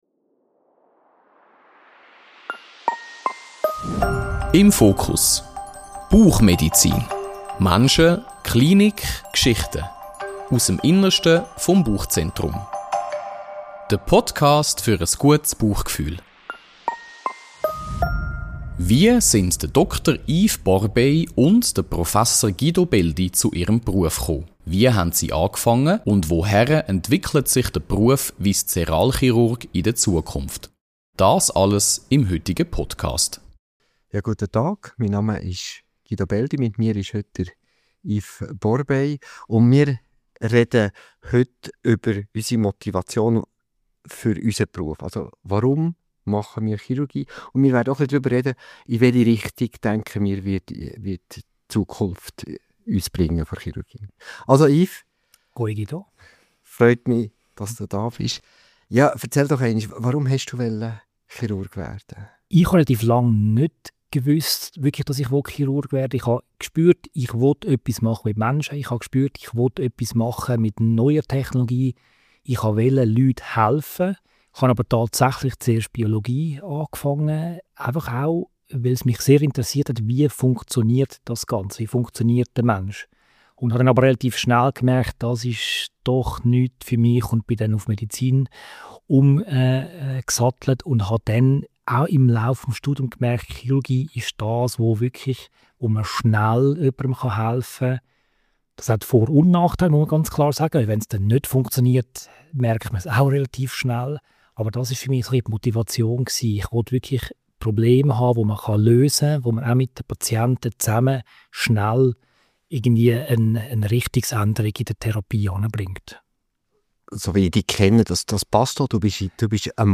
Ein persönliches und zugleich fachlich spannendes Gespräch über Begeisterung, Verantwortung und die Zukunft der Chirurgie.